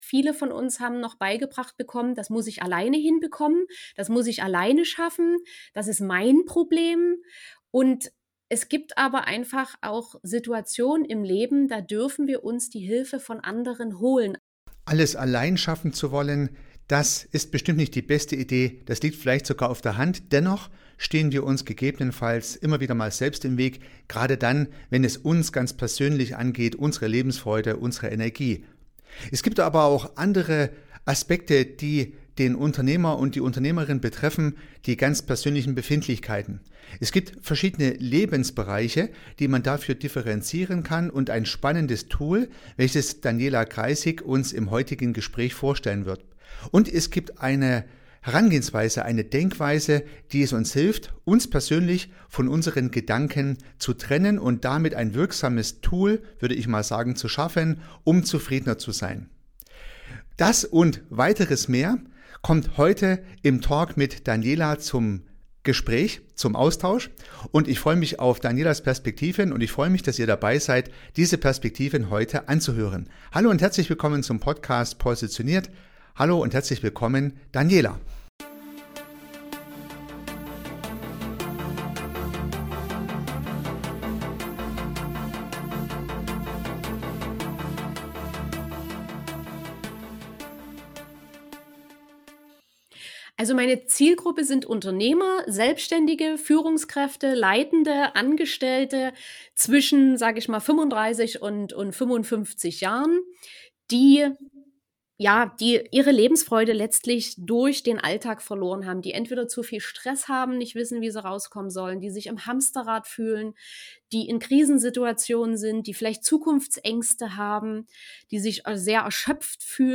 das Interview